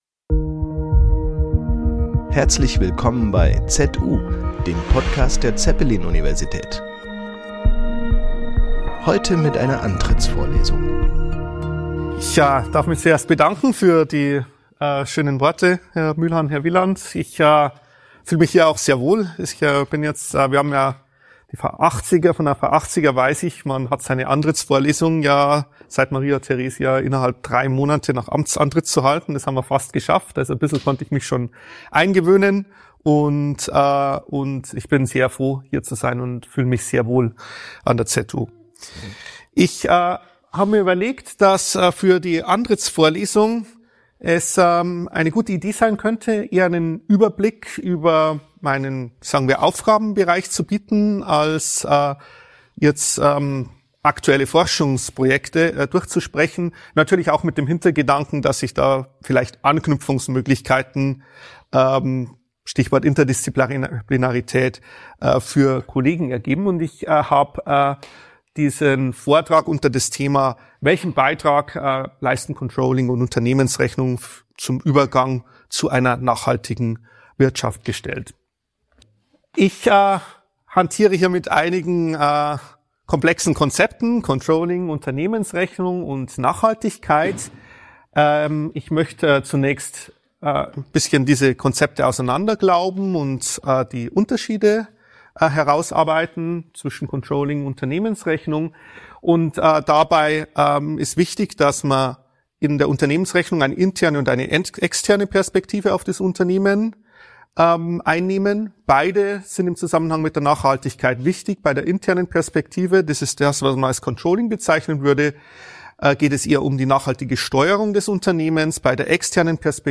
Antrittsv. ~ ZU - Der Podcast der Zeppelin Universität Podcast